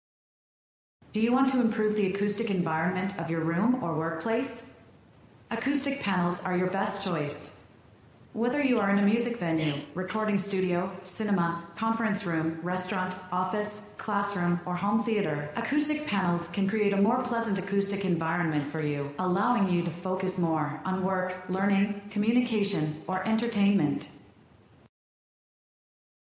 Sound effect of not using INTCO Acoustic Panels
no-denoise.mp3